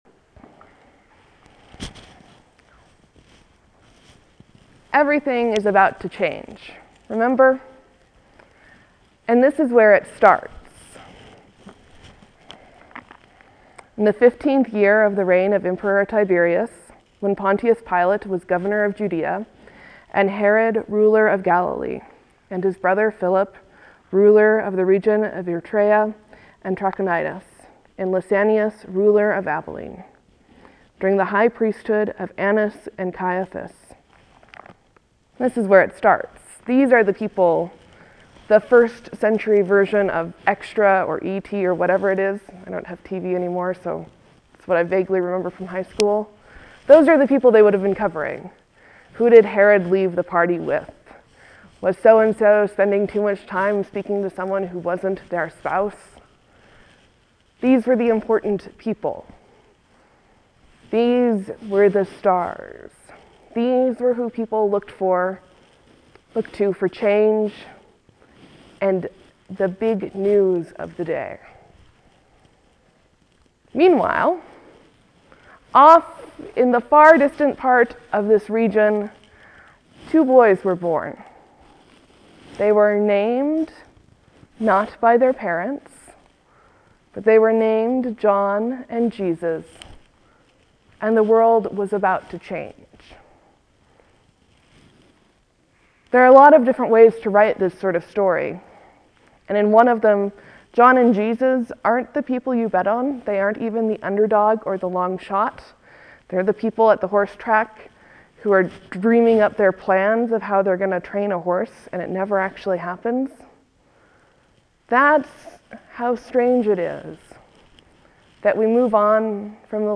(There will be a few moments of silence before the sermon starts.